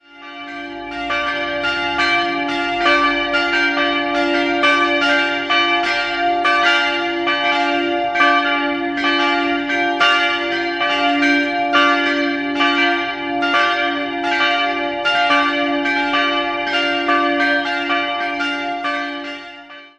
3-stimmiges TeDeum-Geläute: d''-f''-g''